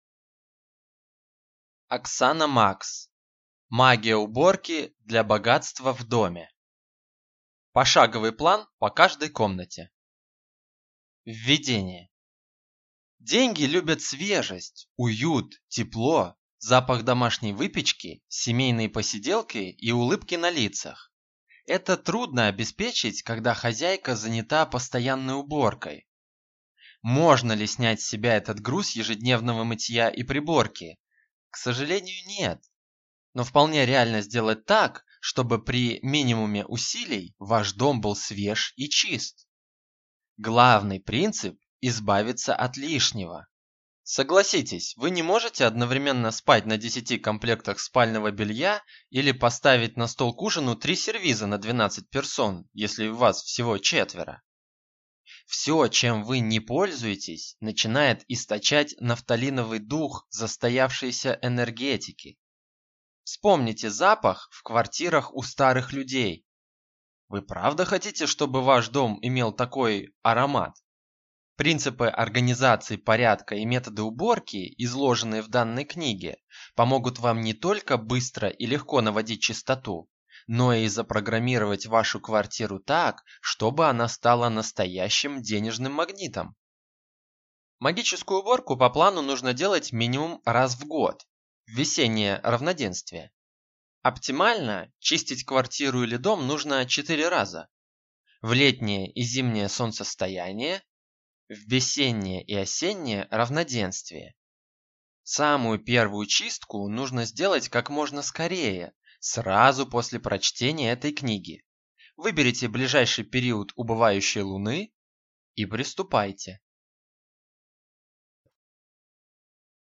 Аудиокнига Магия уборки для богатства в доме | Библиотека аудиокниг